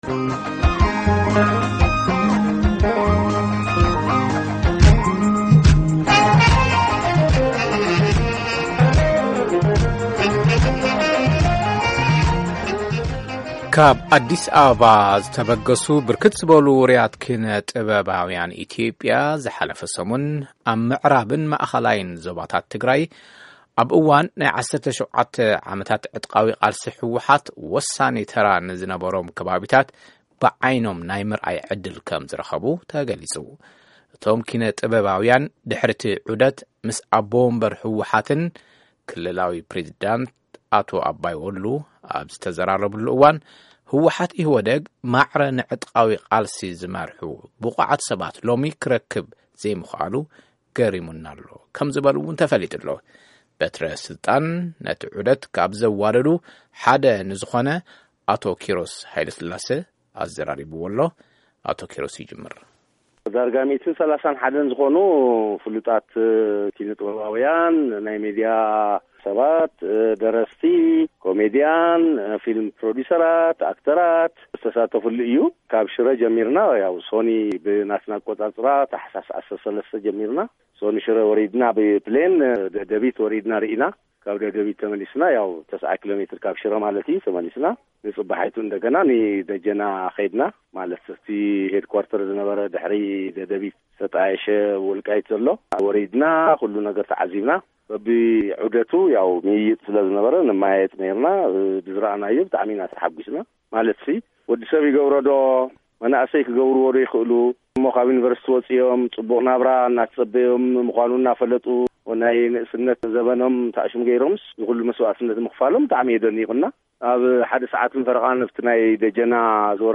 ቃለ-መጠይቅ